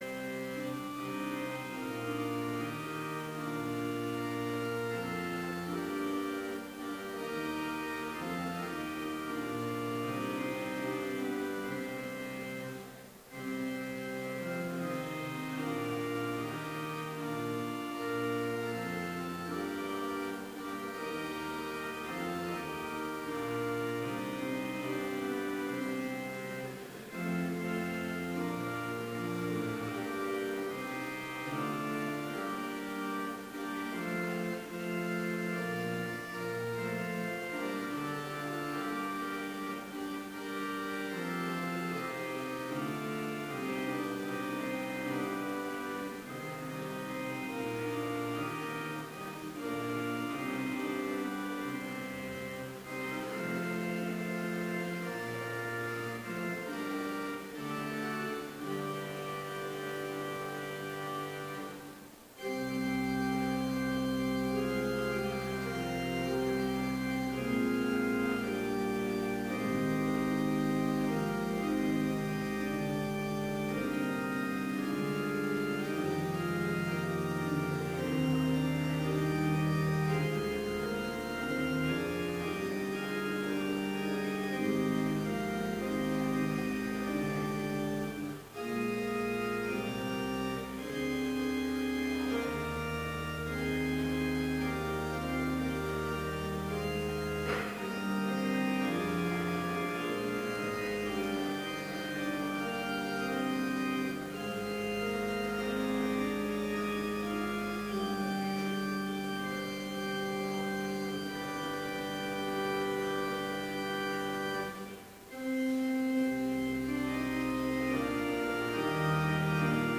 Complete service audio for Chapel - February 9, 2016